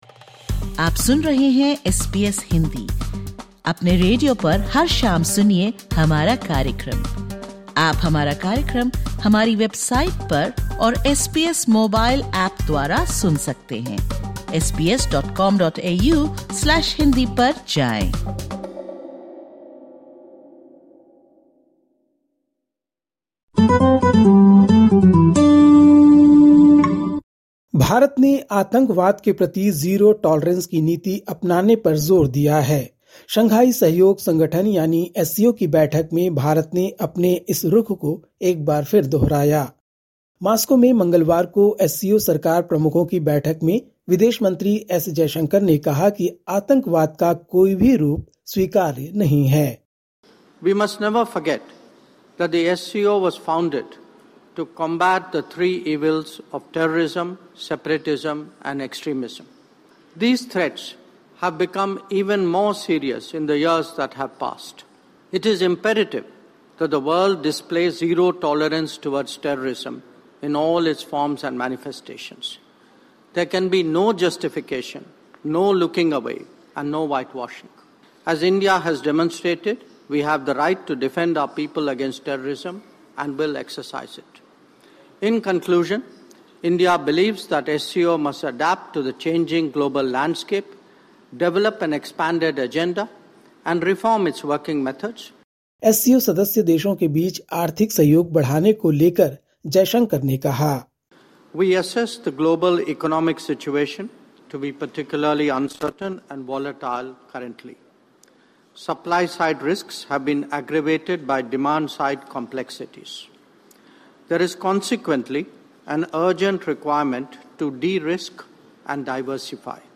Listen to the latest SBS Hindi news from India. 19/11/2025